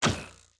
TP_Bow_Shoot_Quick.wav